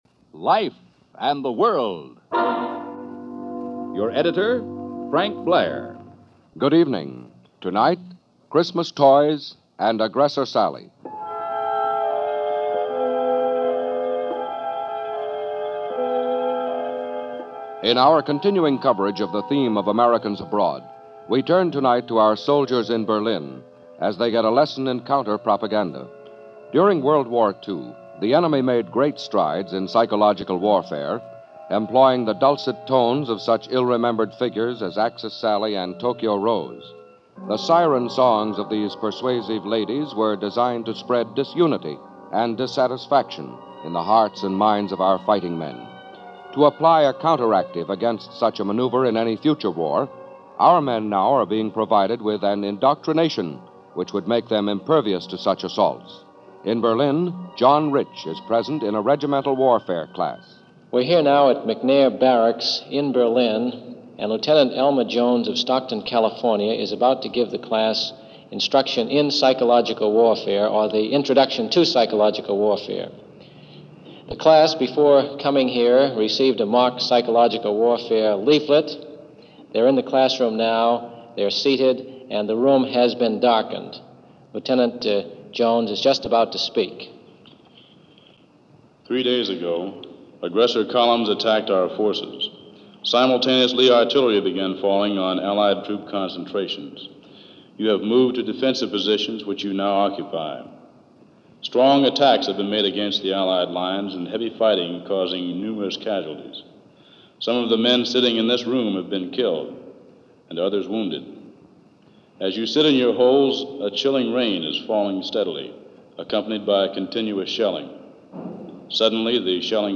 The NBC Radio/Life Magazine co-production Life And The World offered a rather strange piece on propaganda training for the U.S. Army in Germany , with one of the more truly weird examples of an imagined radio broadcast beamed at U.S. troops in an imaginary battlefield. After being reminded of how vigilant we must all be against subliminal suggestions from an unseen enemy, we’re catapulted into Christmas 1957, with thoughts on Christmas’ past.